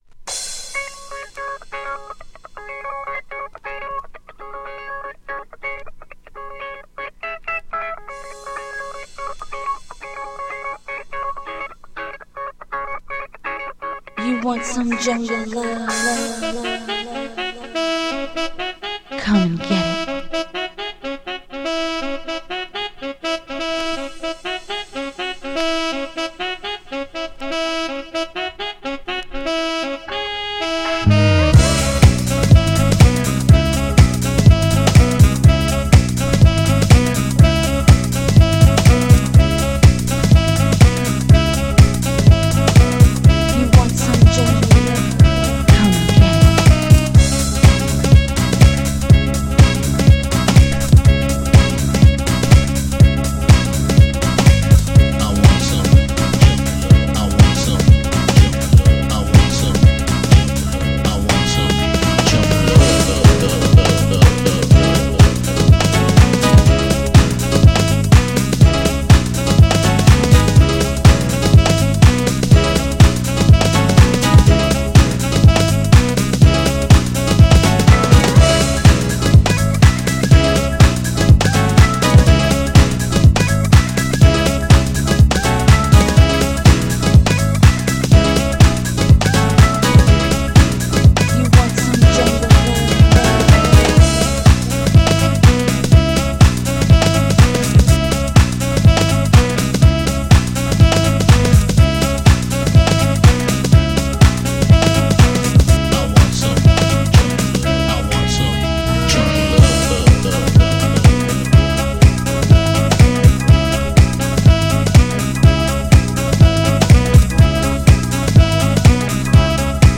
アーバンなSAXやピアノなどがほどよくフィーチャーされたダンサーにも人気のスムースなHOUSE CLASSIC
GENRE House
BPM 121〜125BPM